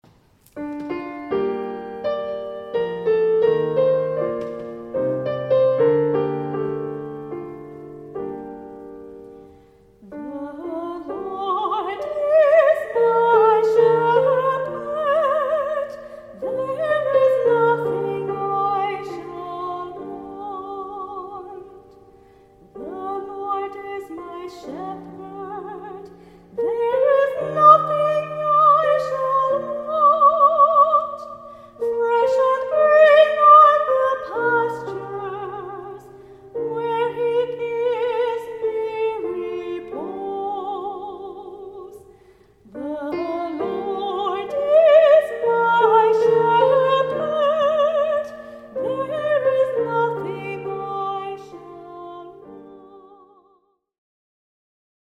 Waterford Soprano